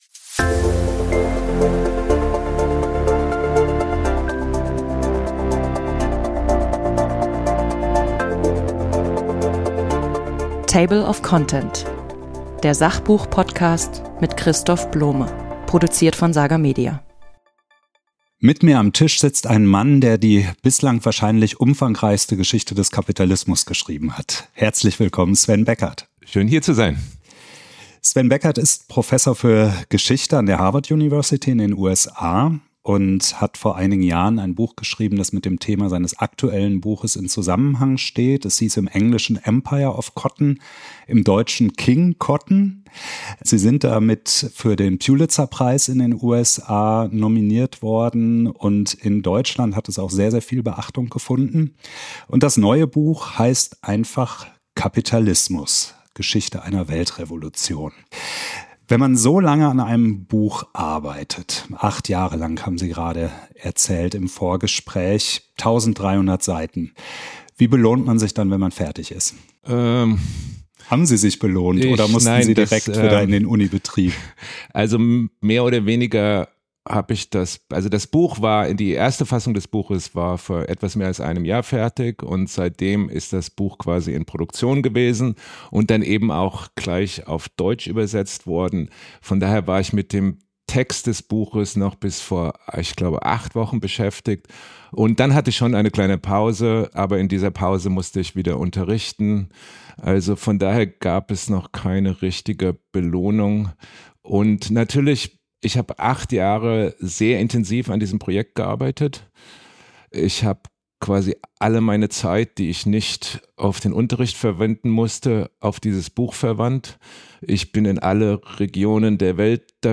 Mit dem deutsch-amerikanischen Historiker Sven Beckert von der Universität Harvard spreche ich über das folgenreichste Ereignis der Menschheitsgeschichte: die Ausbreitung des Kapitalismus